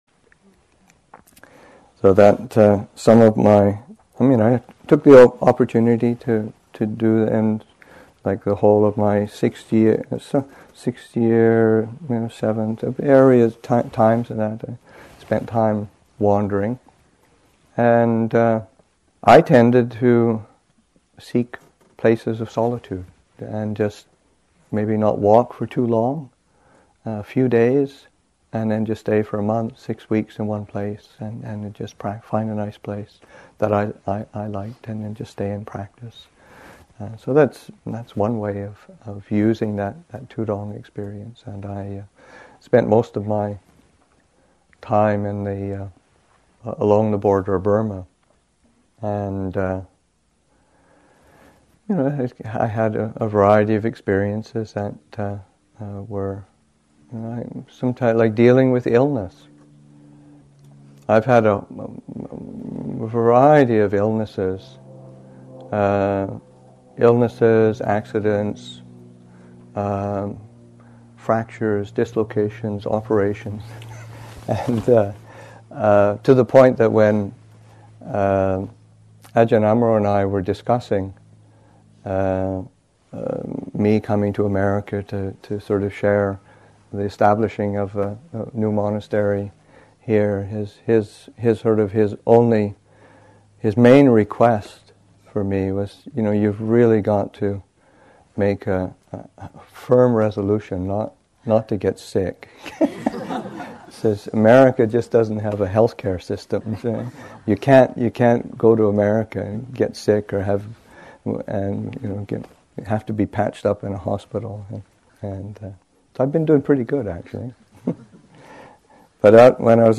Story